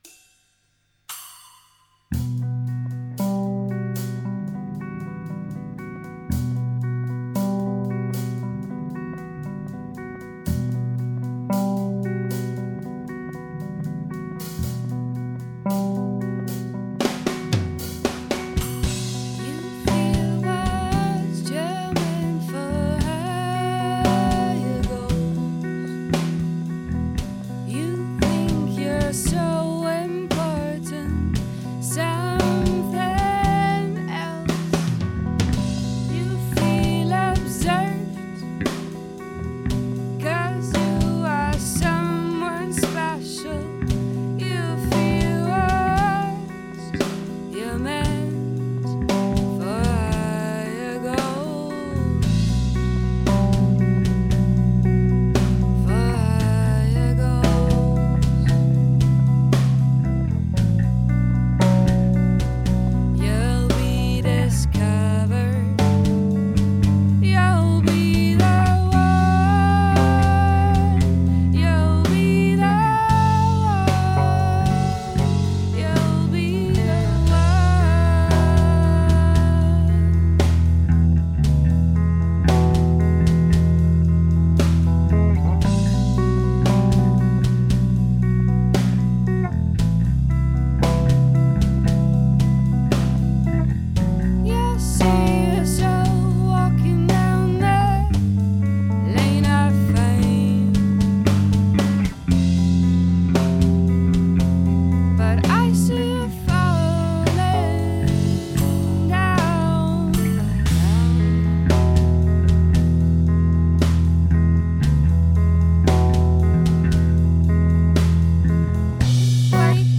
Postrock (ja, immer noch!)
2 Demo-Titel (laufende Aufnahmen 2013)